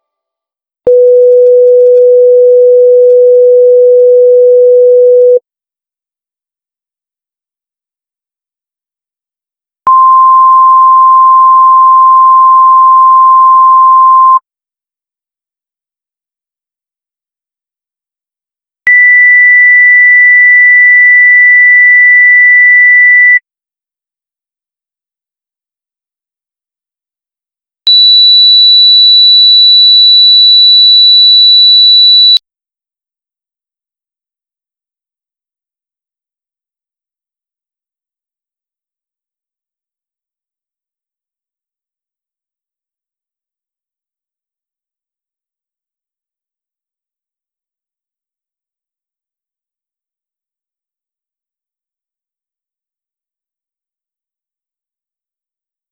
Test-Audition.wav